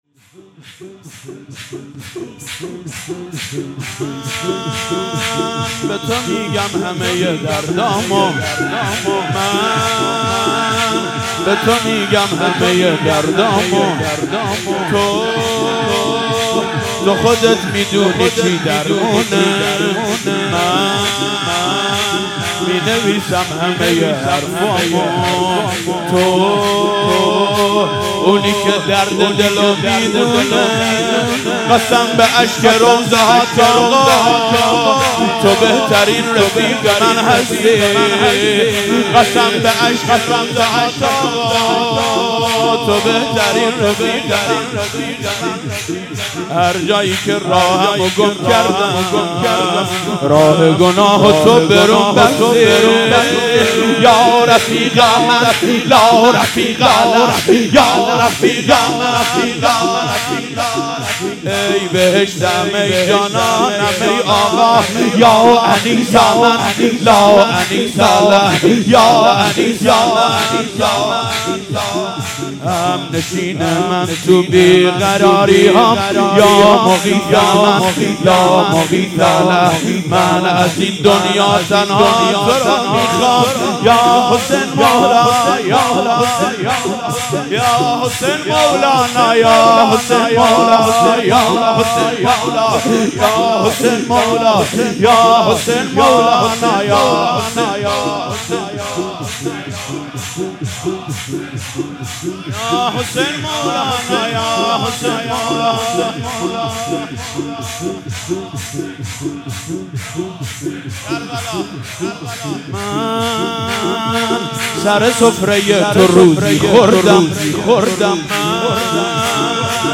مراسم هفتگی/29آذر97